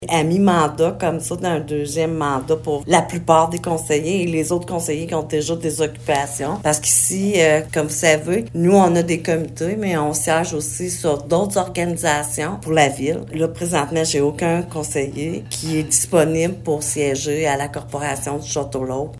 Pour l’instant, aucun conseiller ne s’est porté volontaire. La mairesse de Maniwaki, Francine Fortin, précise que tous les conseillers siègent déjà dans divers comités et ont tous un horaire très chargé :